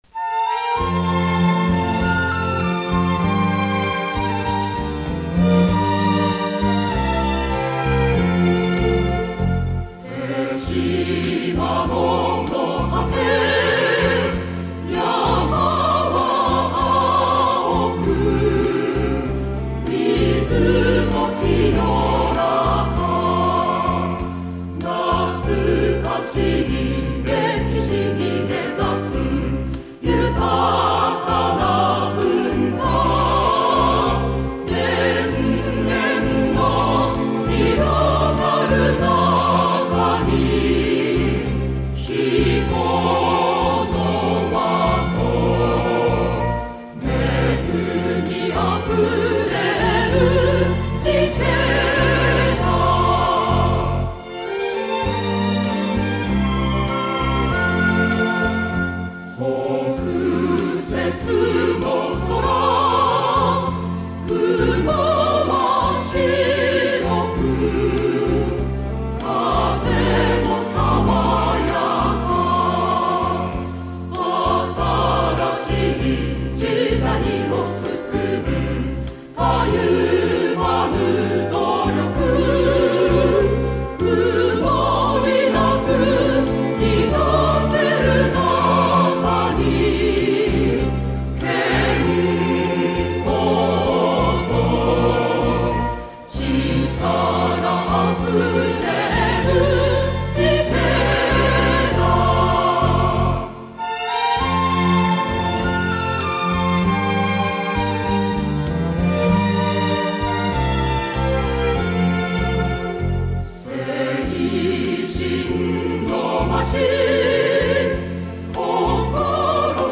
市歌（コーラス、3番まで) (音声ファイル: 3.3MB)